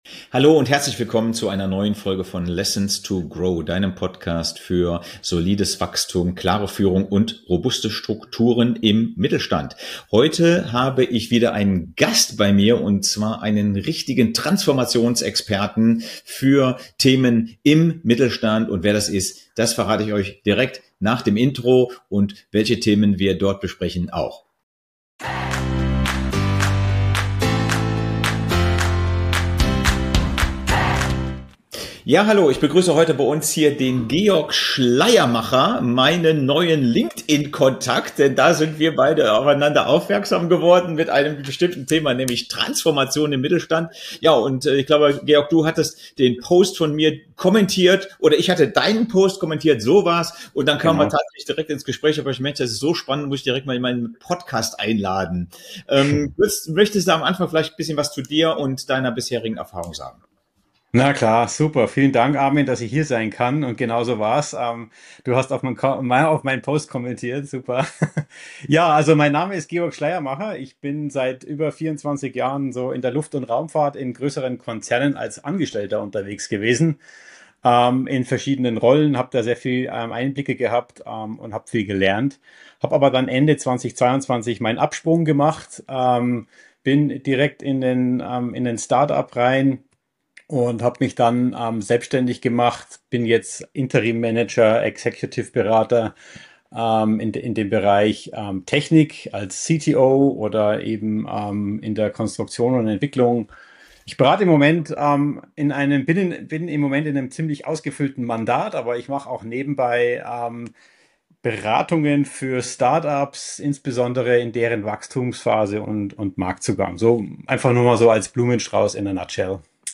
Digitale Transformation: das sind die kritischen Erfolgsfaktoren - Gespräch